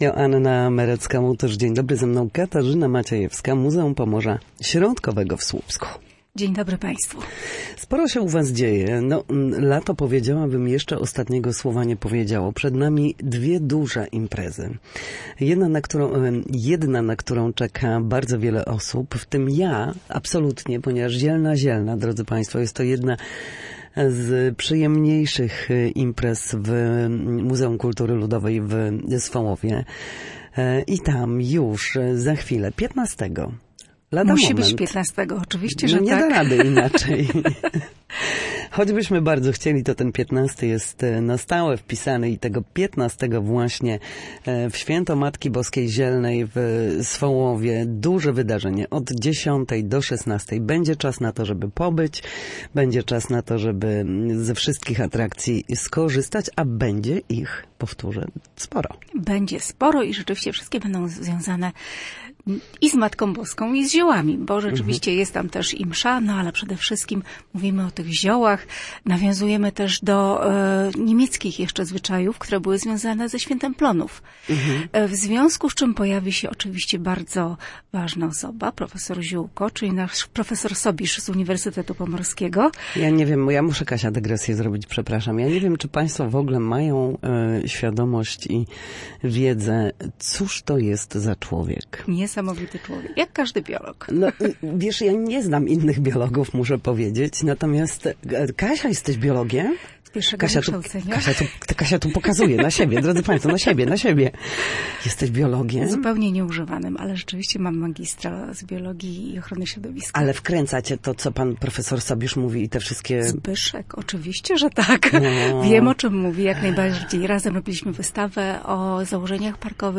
Na antenie Studia Słupsk